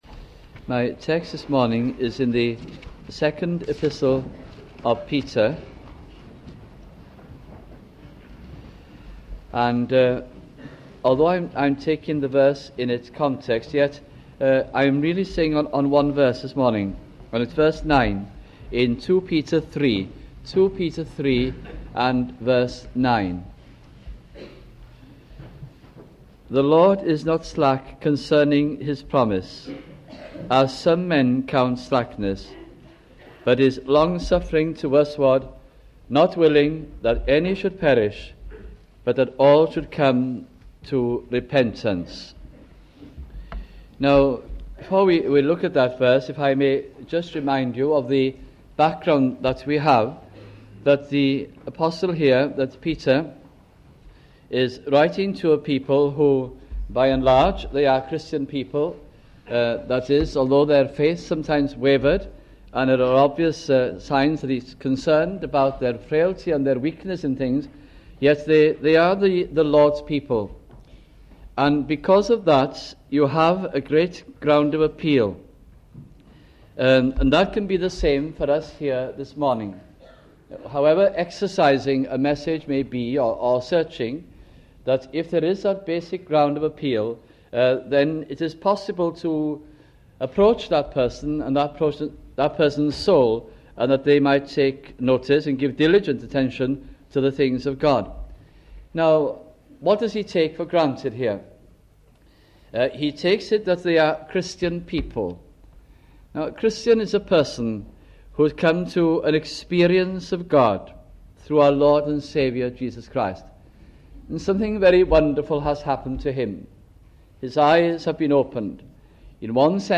» Second Epistle of Peter Series 1983 » sunday morning messages